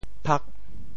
“曝”字用潮州话怎么说？
曝 部首拼音 部首 日 总笔划 19 部外笔划 15 普通话 pù bào 潮州发音 潮州 pag8 文 中文解释 曝光 [exposure] 使感光纸或摄影胶片感光 曝pù ⒈晒：冬～。